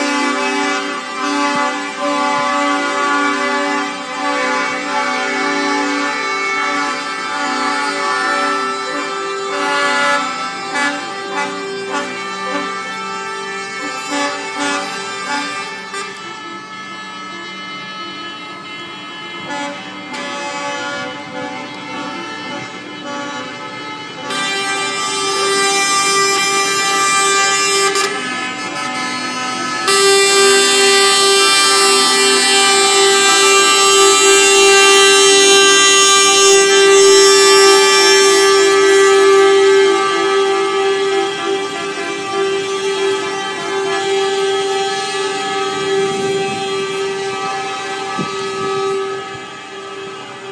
Rather a noisy protest in Lisbon
94456-rather-a-noisy-protest-in-lisbon.mp3